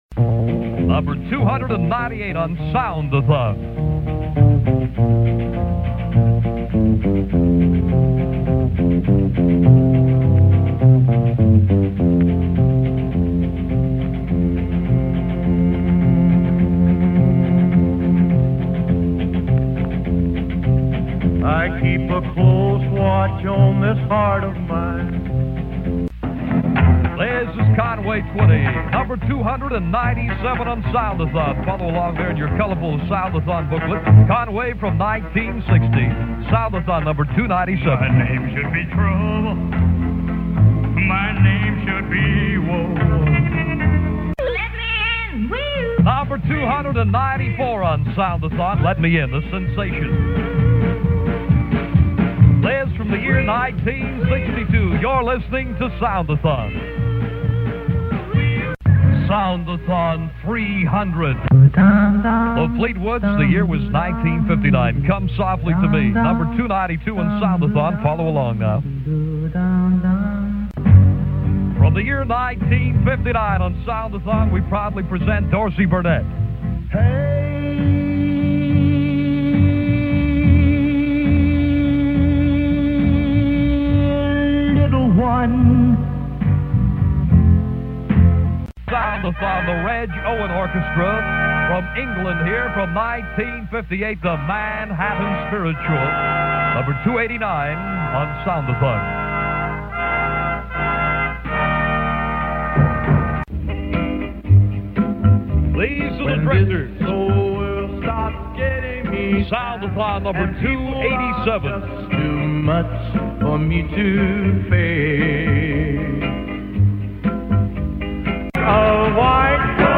The C-FUN Good Guys introduce the songs in these audio clips recorded directly from the radio during the actual event in December 1963.
(15 min 54 sec) If your browser does not support audio player click here DJs heard in this Montage: 1.